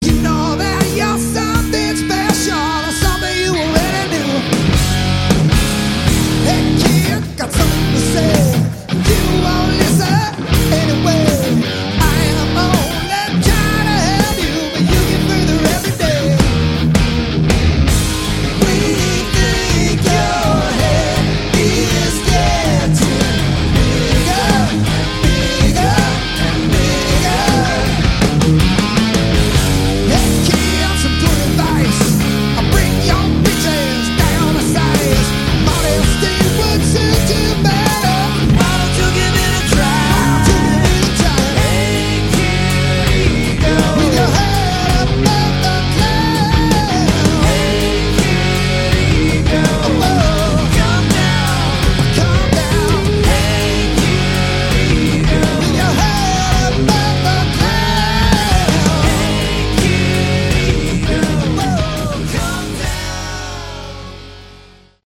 Category: Hard Rock
vocals
guitars
bass
drums
Recorded live in 2008